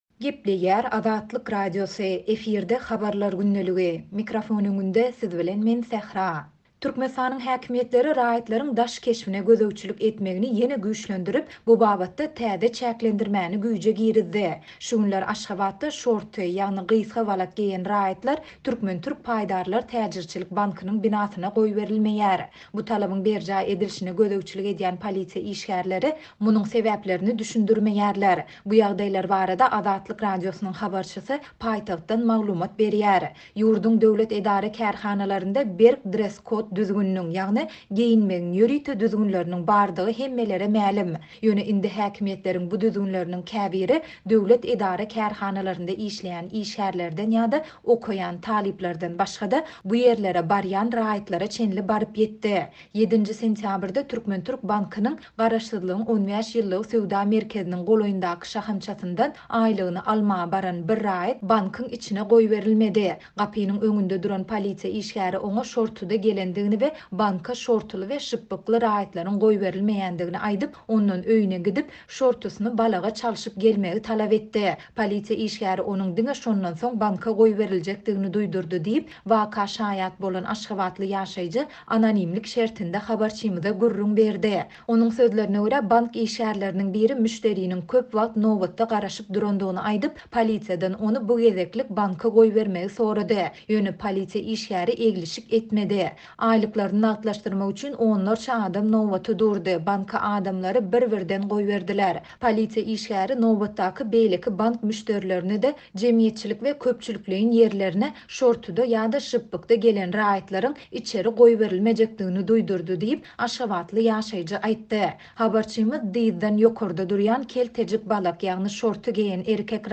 Bu ýagdaýlar barada Azatlyk Radiosynyň habarçysy paýtagtdan maglumat berýär.